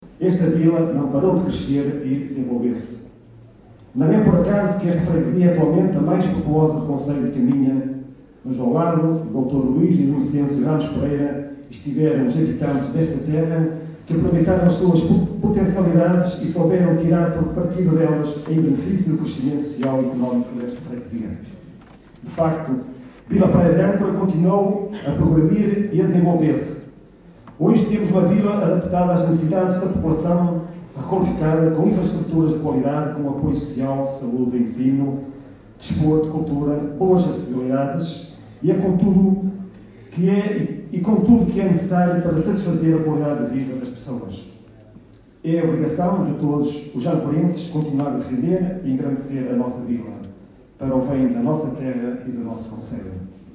Vila Praia de Âncora comemorou ontem o 95º aniversário da sua elevação com uma sessão solene que decorreu no Cineteatro dos Bombeiros Voluntários de Vila Praia de Âncora e que contou com a presença de representantes da Câmara, Assembleia Municipal, deputados da nação, entre outros convidados e população.
Carlos Castro, presidente da Junta de freguesia de Vila Praia de Âncora, abriu a sessão sublinhando que não iria fazer um discurso político, considerando que isso é o que menos interessa às pessoas.